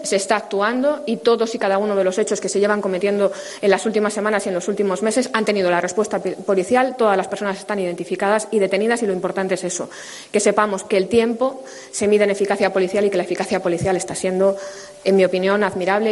Mercedes González, Delegada del Gobierno en Madrid